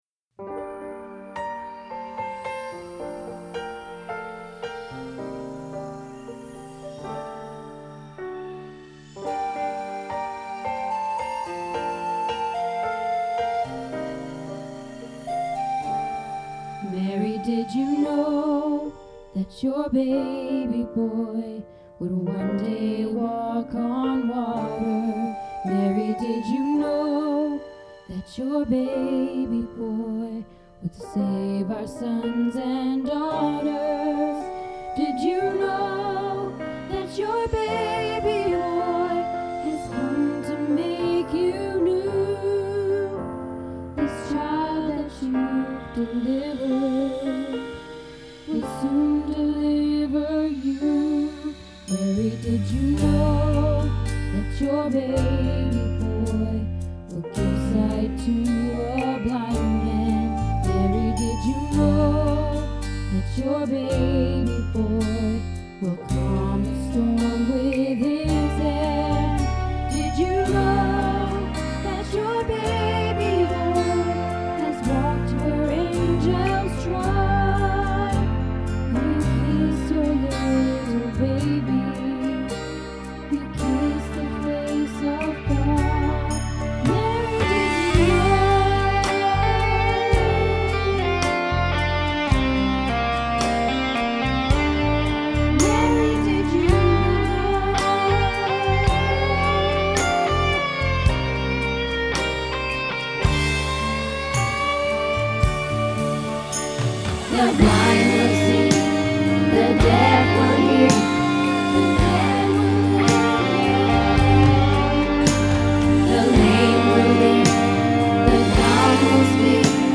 Mary Did You Know - Bass